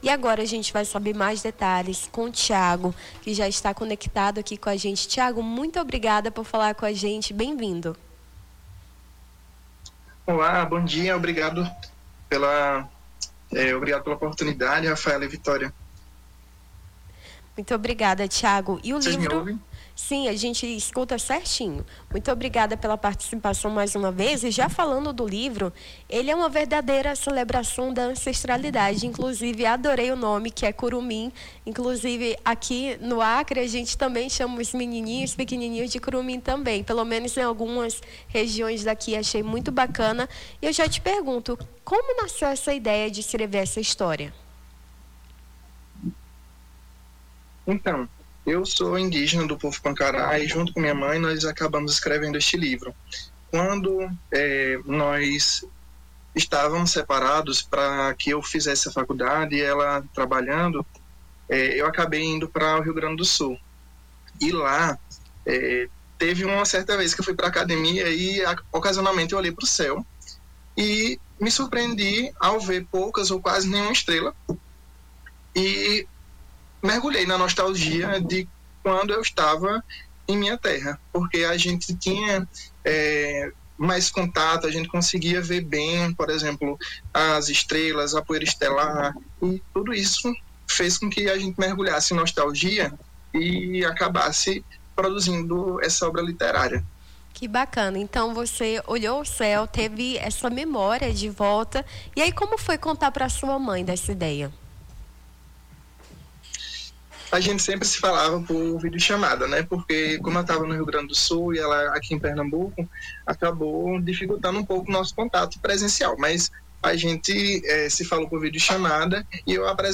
Nome do Artista - CENSURA - ENTREVISTA (LIBRO INFANTIL ANCESTRALIDADES) 20-10-25.mp3